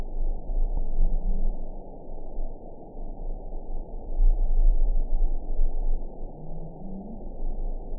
event 910236 date 01/17/22 time 13:30:18 GMT (3 years, 10 months ago) score 7.12 location TSS-AB08 detected by nrw target species NRW annotations +NRW Spectrogram: Frequency (kHz) vs. Time (s) audio not available .wav